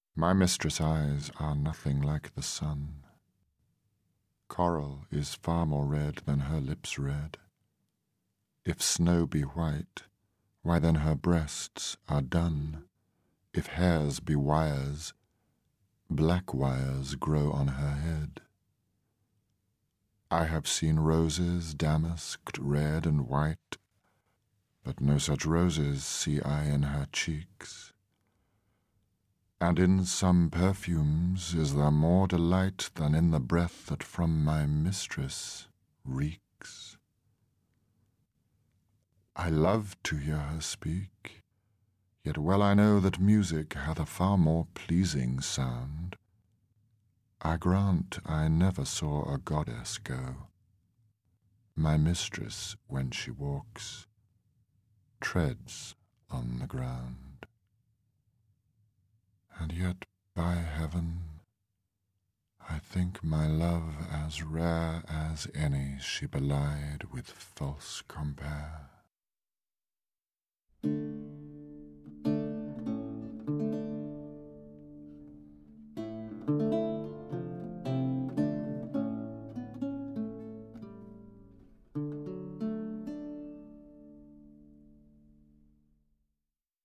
Alan Rickman reads Shakespeare’s Sonnet 130